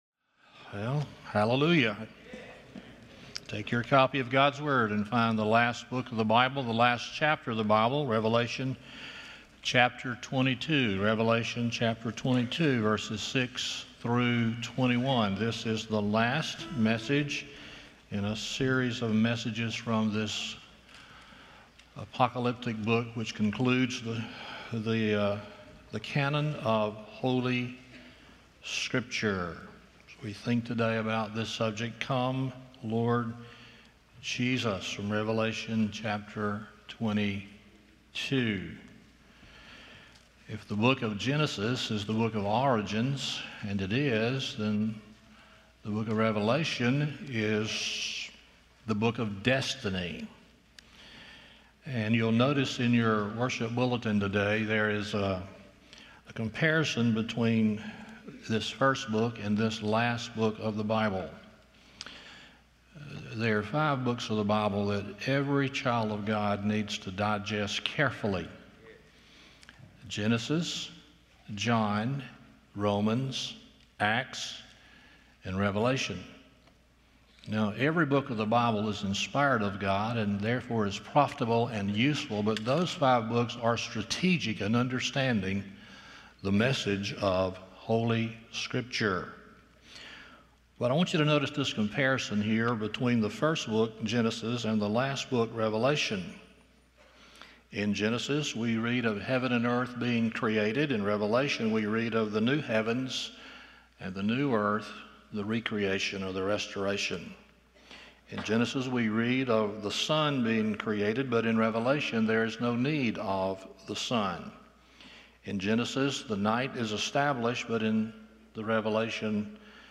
Revelation 22:6-21 Service Type: Sunday Morning 1.